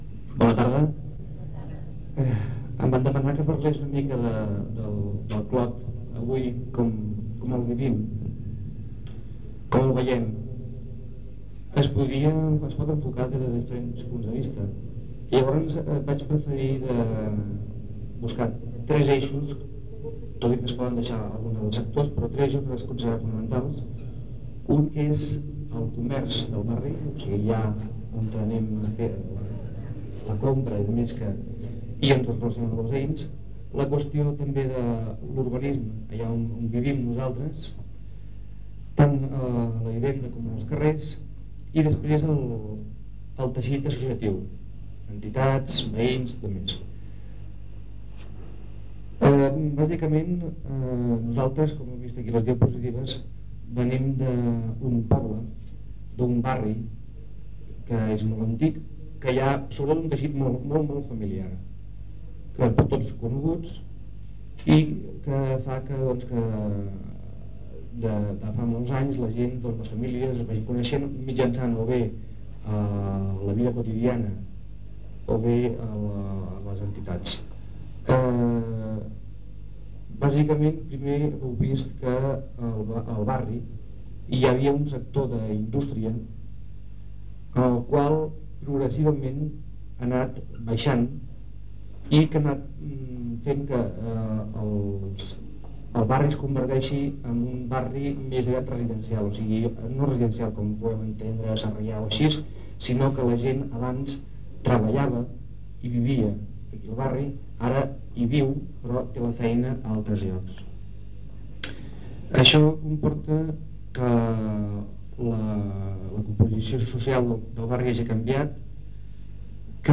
Corpus Oral de Registres (COR). EDUC4. Conferència